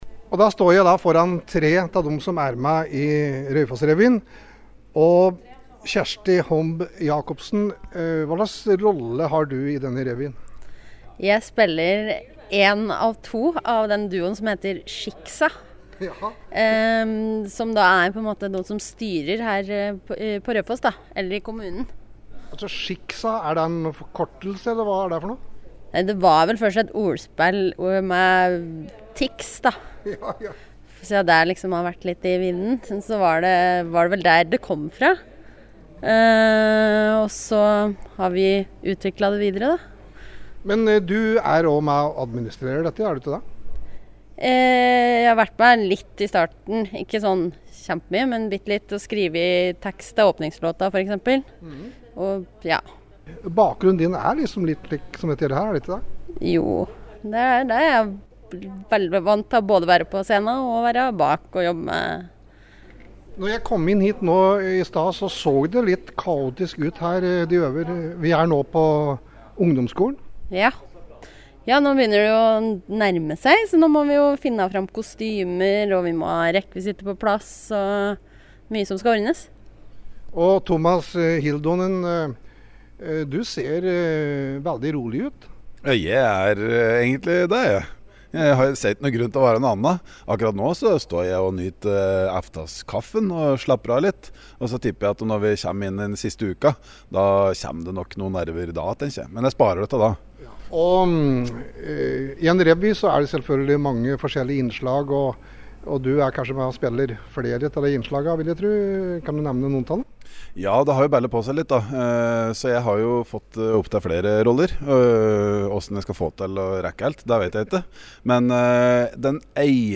Innslag
Jeg var på en av øvelsene i forrige uke.